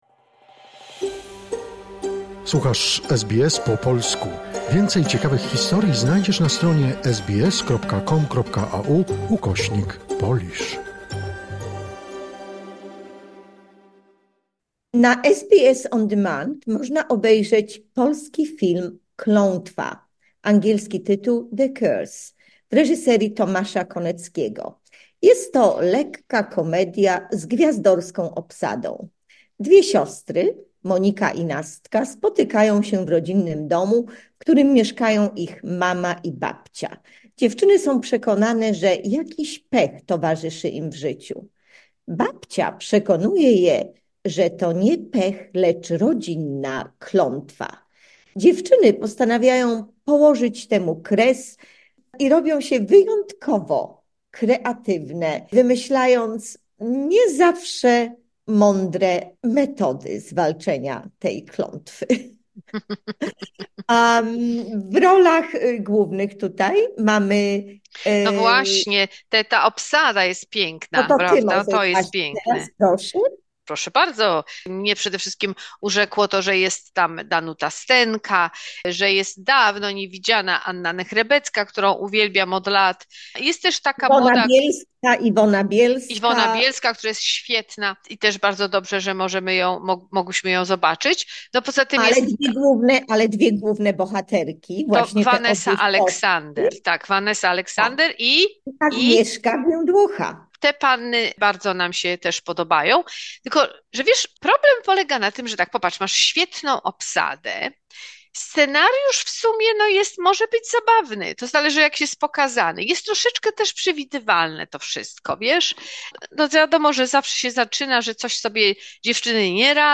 "Klątwa" - recenzja filmowa